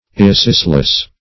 Irresistless \Ir`re*sist"less\